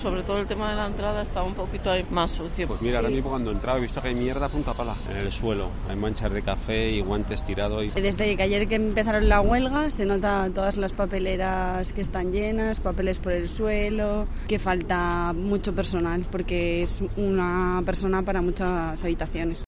Las personas que acuden al Hospital Clínico San Carlos reaccionan a las consecuencias de la huelga